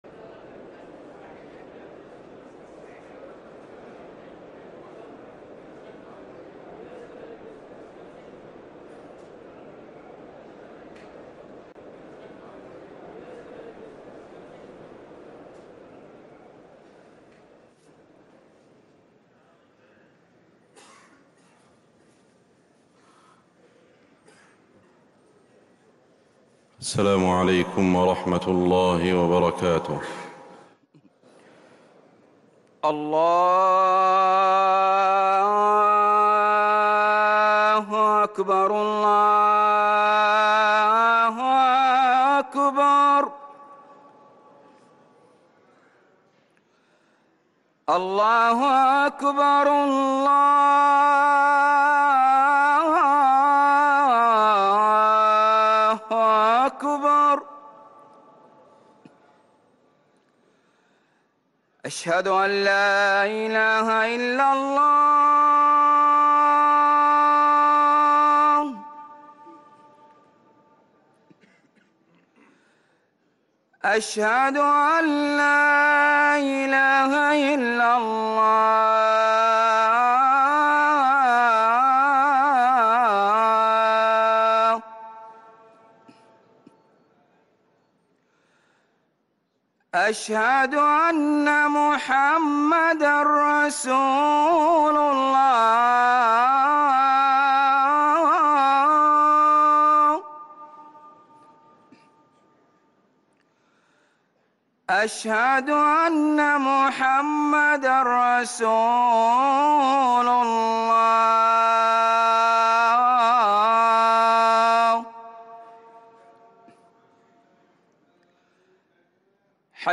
أذان الجمعة الثاني للمؤذن سعود بخاري 29 جمادى الأولى 1444هـ > ١٤٤٤ 🕌 > ركن الأذان 🕌 > المزيد - تلاوات الحرمين